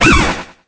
Cri de Pomdrapi dans Pokémon Épée et Bouclier.